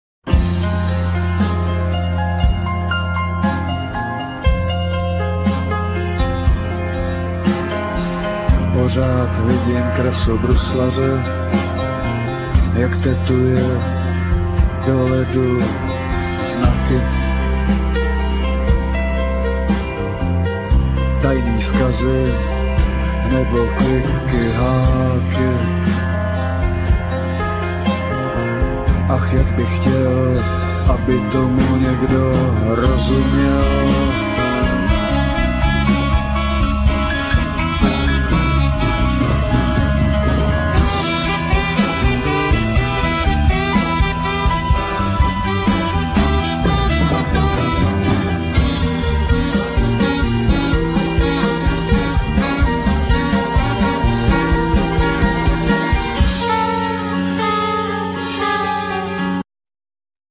Vocal,Piano
Drums,Nastroje,Djembe
Bass
Saxophone